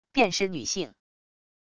便是女性wav音频